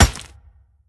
box_marble_open-1.ogg